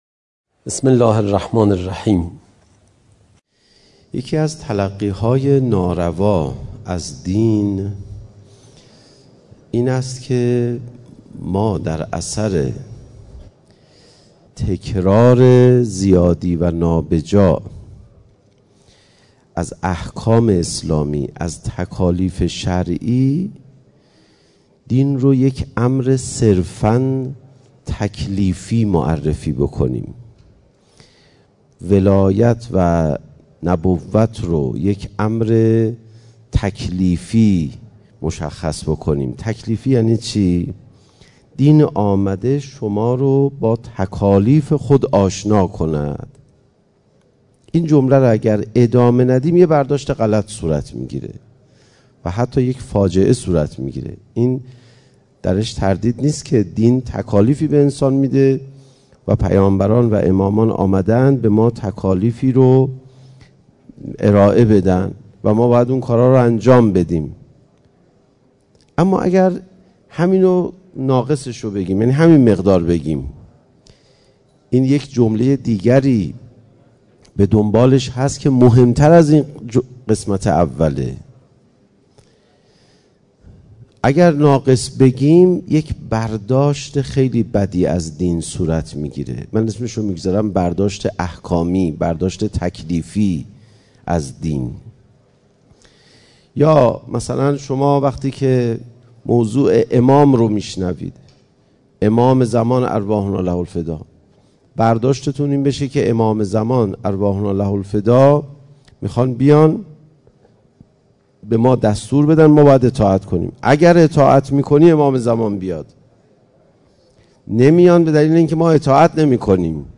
مکان: مسجد دانشگاه امیرکبیر